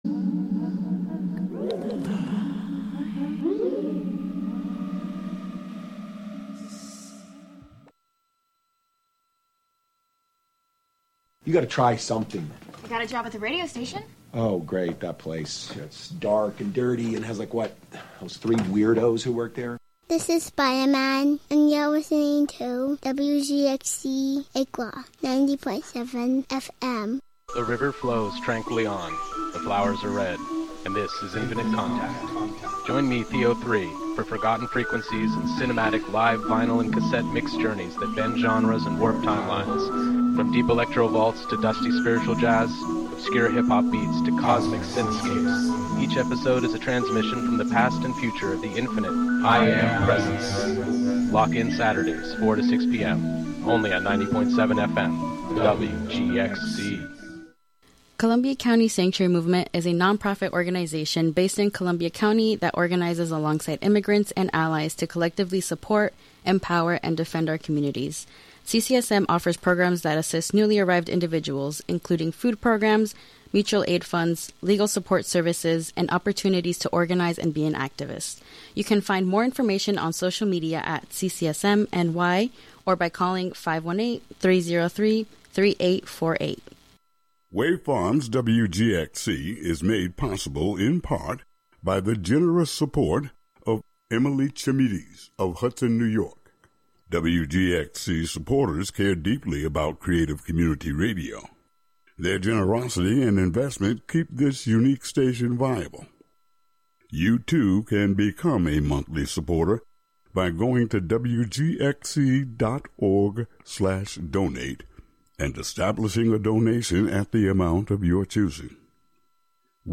plays an eclectic mix of old and new blues, electronica, pop, RnB, country, Latin, rock, rap, and a bit of classical music live from WGXC's Catskill studio.